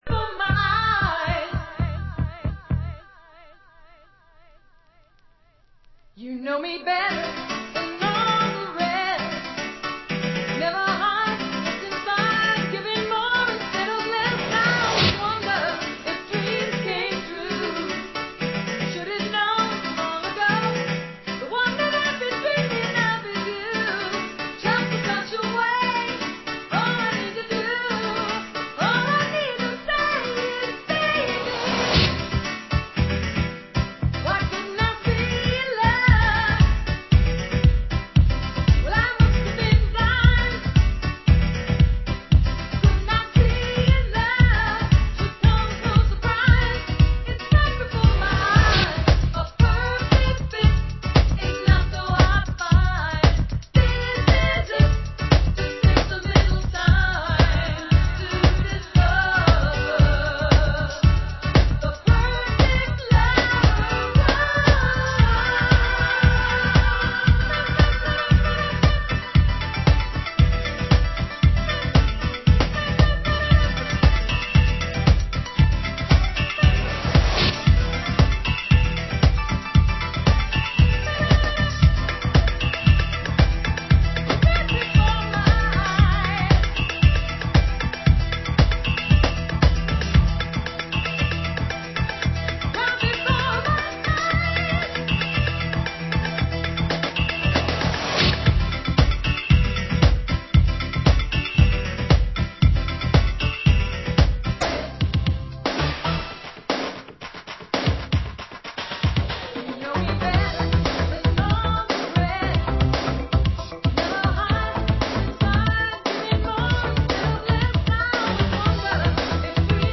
Genre: UK House